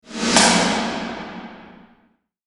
VEC3 Reverse FX
VEC3 FX Reverse 10.wav